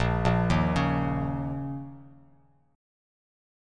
A#mbb5 chord